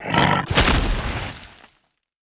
boss_lang_die.wav